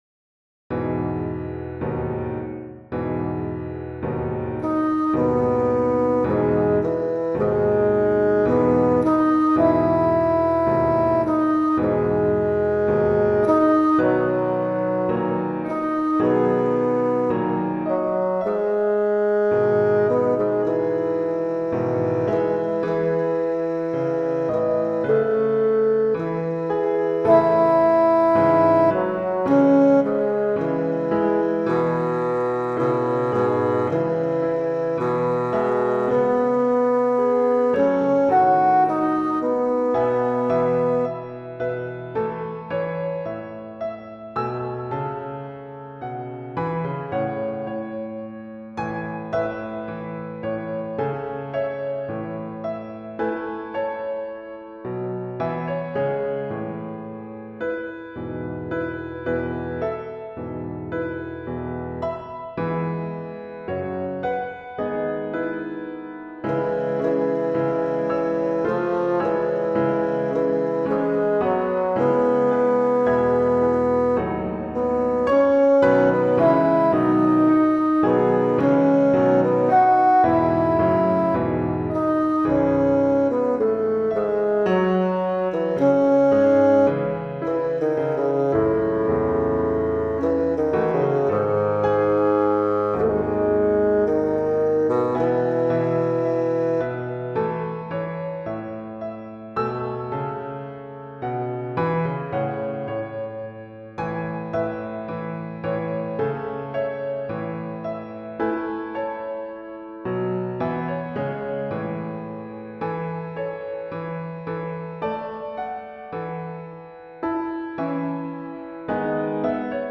Tonart: E-Dur / Tempo: Andante tranquillo
- In der Demoaufnahme wurde die Gesangstimme durch ein Fagott ersetzt.